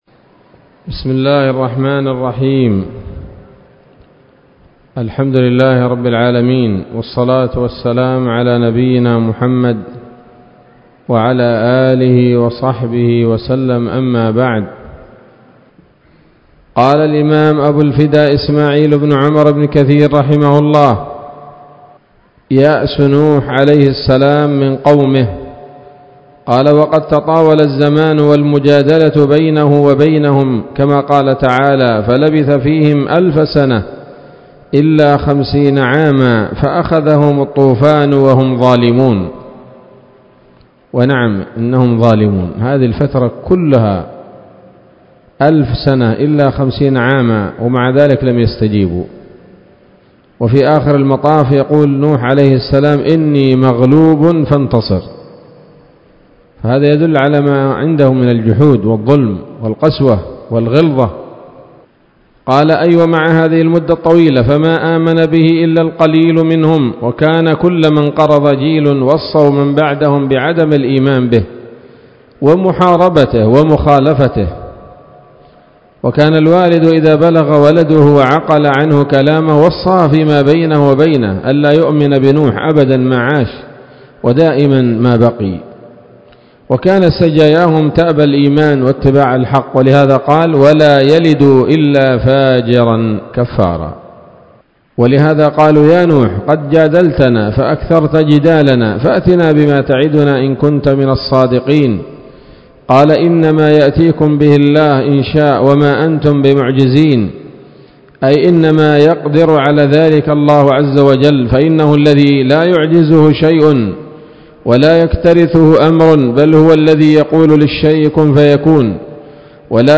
الدرس الثالث والعشرون من قصص الأنبياء لابن كثير رحمه الله تعالى